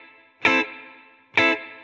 DD_TeleChop_130-Amin.wav